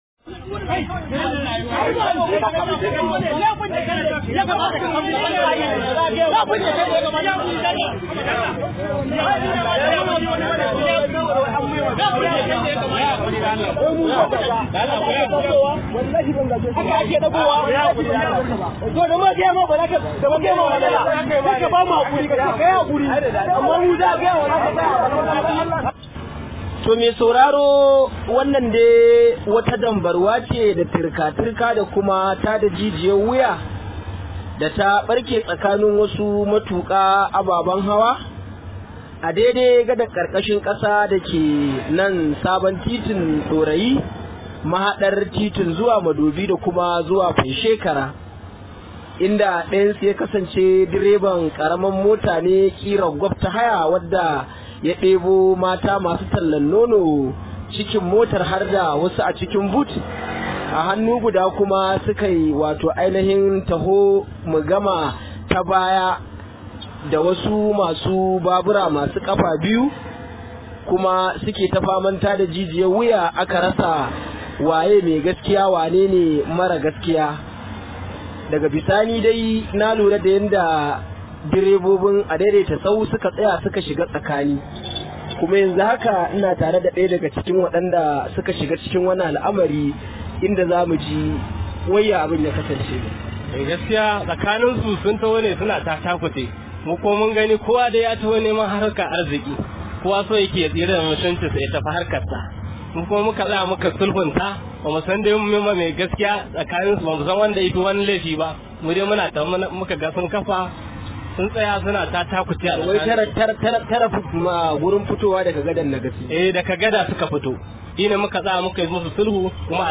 Rahoto: Rashin hakuri ke janyo rigima tsakanin masu Ababen hawa – Mai Adaiadaita